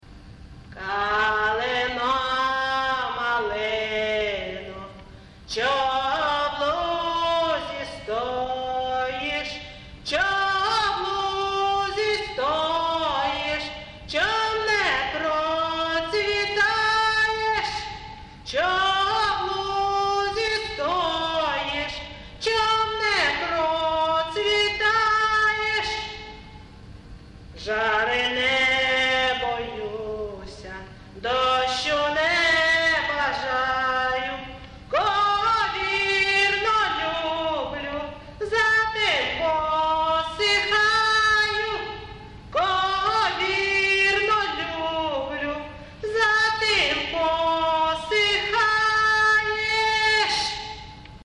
ЖанрПісні з особистого та родинного життя
Місце записус. Ковалівка, Миргородський район, Полтавська обл., Україна, Полтавщина